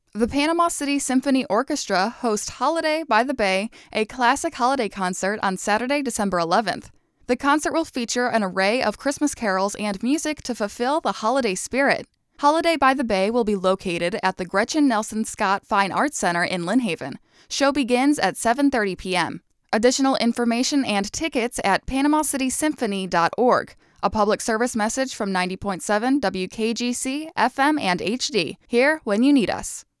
PC Symphony Orchestra Holiday by the Bay PSA
PC-Symphony-Orchestra-Holiday-by-the-Bay-PSA.wav